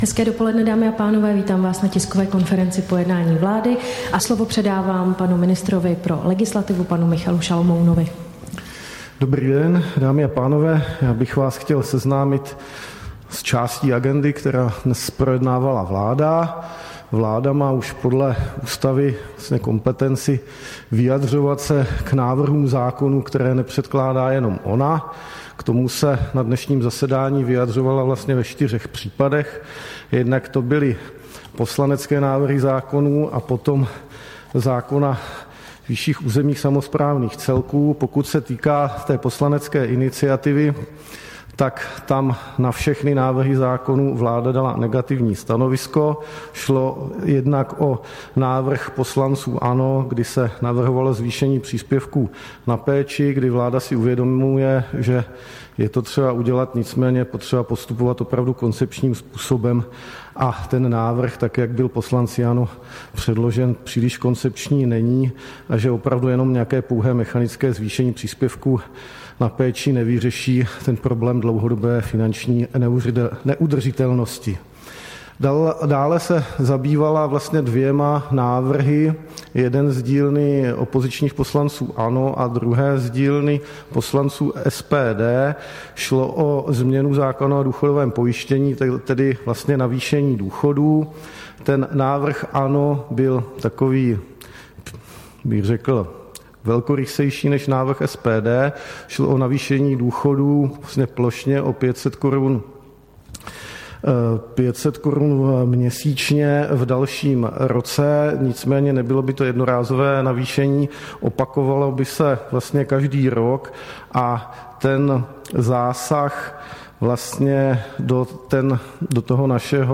Tisková konference po jednání vlády, 8. listopadu 2023